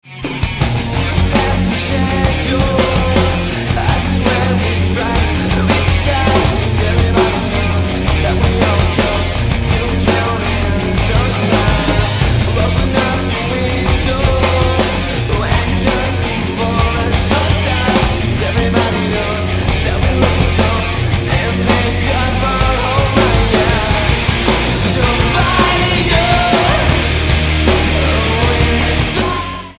STYLE: Pop
Fine singer, fine band, fine songs.
melodic pop/rock